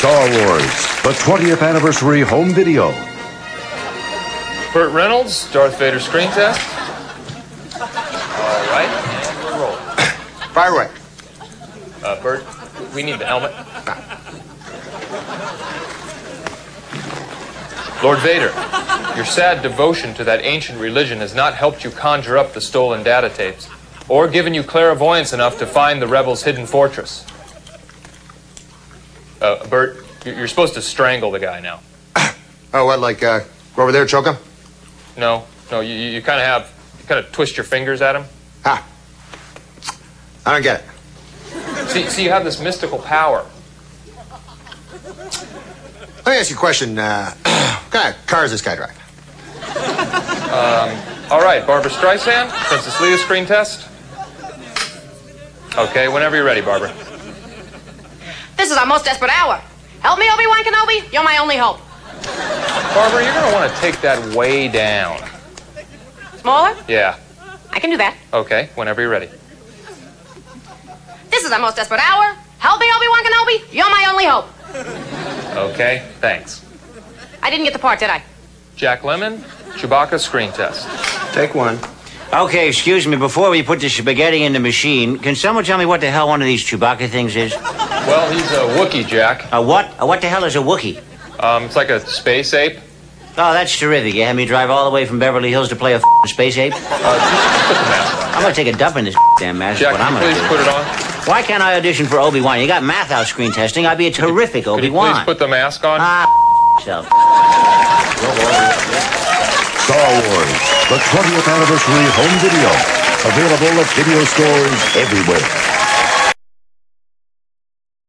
Do you want to hear how good our Mr. Spacey is at doing impressions?
Do you want to see how good our Mr. Spacey is at imitating Jack Lemmon?